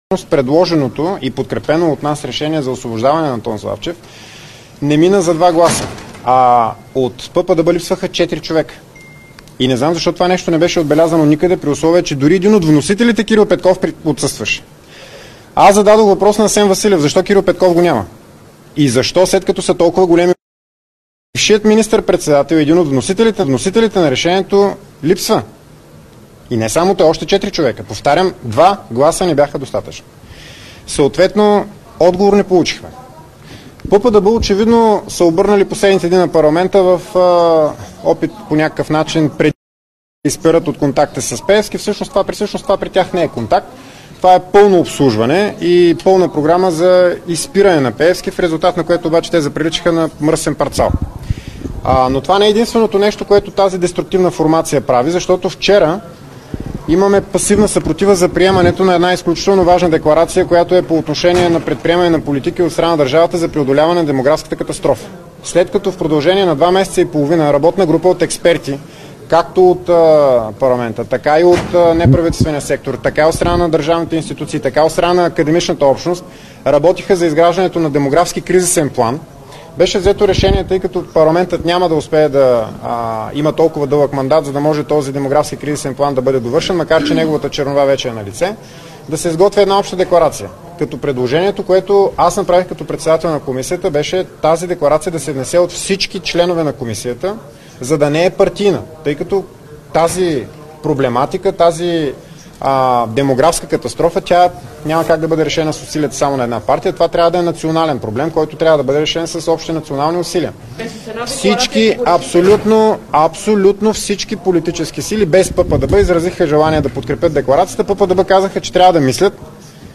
9.05 - Заседание на Народното събрание.
- директно от мястото на събитието (Народното събрание)